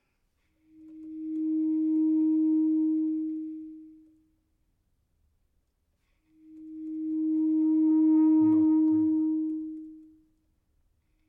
• Genres: Classical
baritone voice, bass clarinet, trombone, trumpet
• Recorded at Oktaven Audio